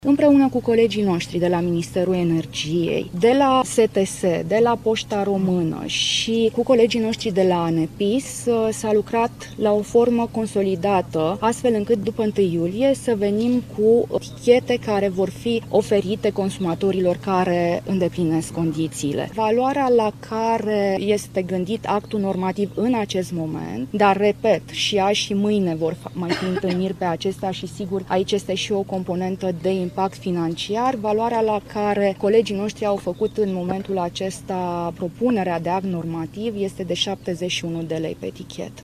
Ministra Muncii, Simona Bucura Oprescu: Valoarea la care este gândit actul normativ în acest moment este de 71 de lei